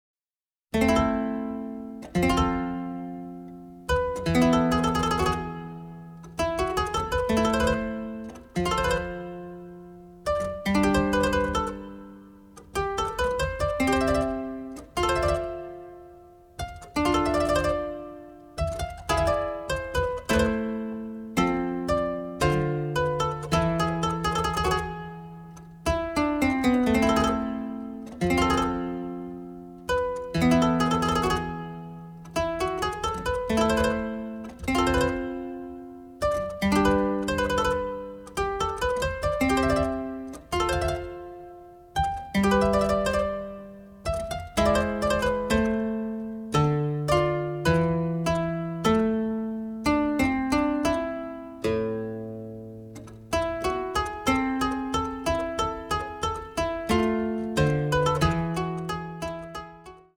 mixed in mono